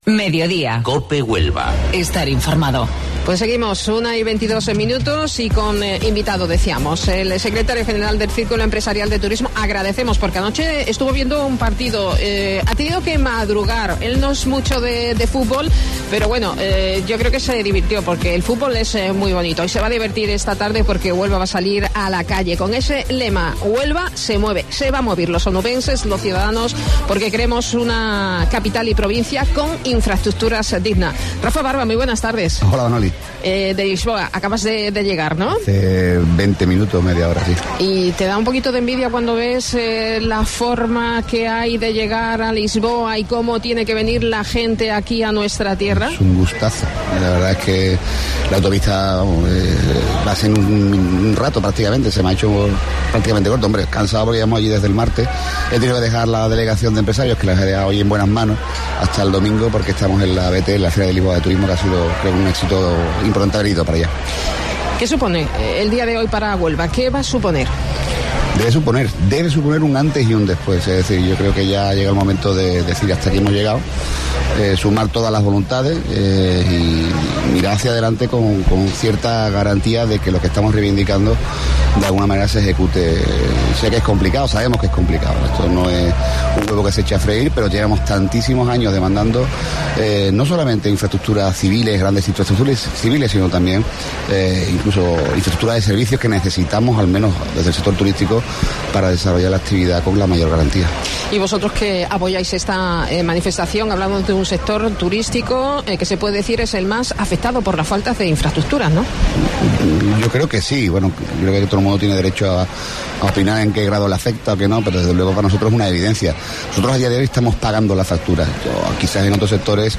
AUDIO: Mediodía Cope se ha realizado hoy desde la Plaza de las Monjas y con motivo de la manifestación en favor de las infraestructuras